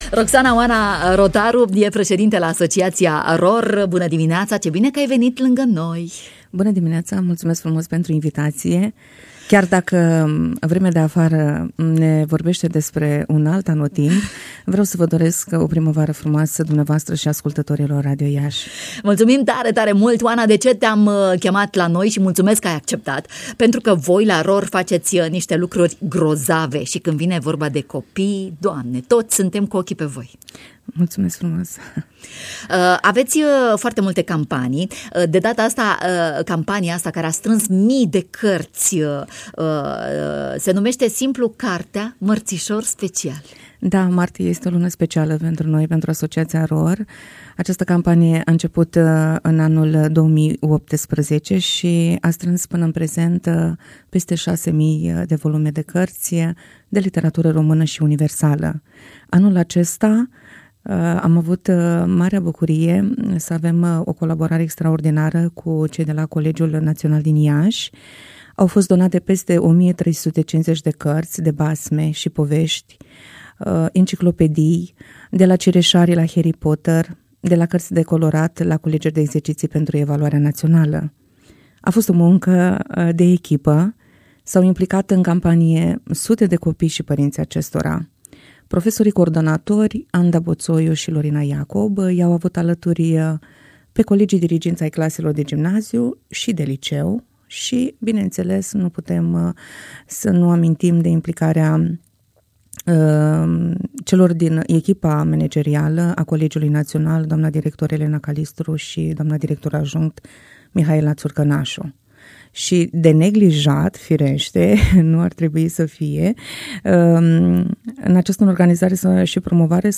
s-a auzit astăzi în matinalul Radio România Iași